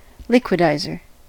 liquidizer: Wikimedia Commons US English Pronunciations
En-us-liquidizer.WAV